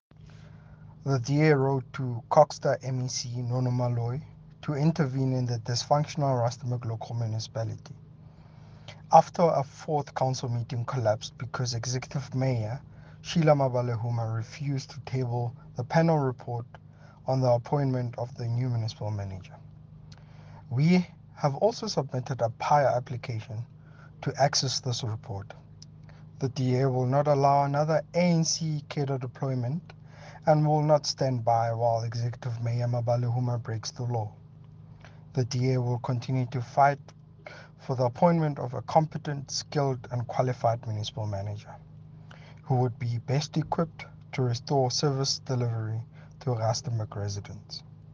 Note to Broadcasters: Please find linked soundbites in
English and Setswana by Cllr Nelio Nhacuangue.